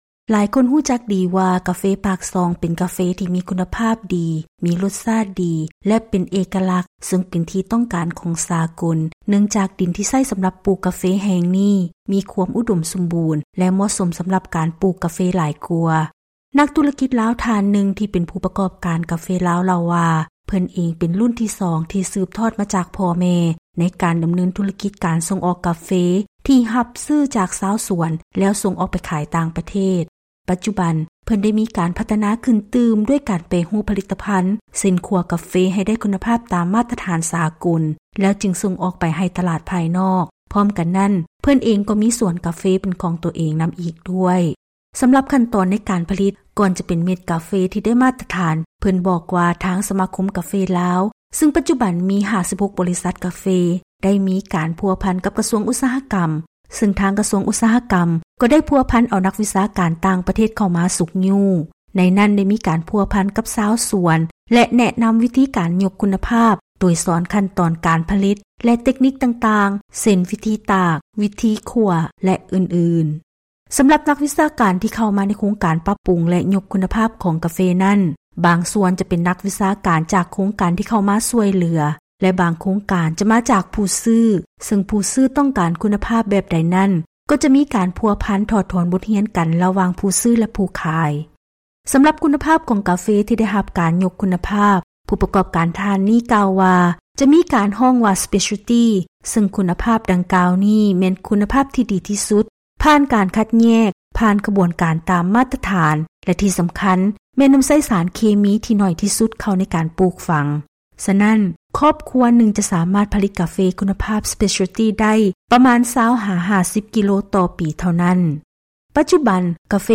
ເຊີນຮັບຟັງລາຍງານກ່ຽວກັບ ຂັ້ນຕອນການຜະລິດ ເພື່ອສົ່ງອອກກາເຟເມັດ ທີ່ມີຄວາມຕ້ອງການໃນອັນດັບຕົ້ນໆ ຈາກປາກຊ່ອງ.
ນັກທຸລະກິດລາວທ່ານນຶ່ງ ທີ່ເປັນຜູ້ປະກອບການກາເຟລາວເລົ່າວ່າ ເພິ່ນເອງເປັນລຸ້ນທີ່ສອງທີ່ສືບທອດຈາກພໍ່ແມ່ໃນການດໍາເນີນທຸລະກິດການສົ່ງອອກກາເຟທີ່ຮັບຊື້ຈາກຊາວສວນ ແລ້ວສົ່ງອອກໄປຂາຍຕ່າງປະເທດ.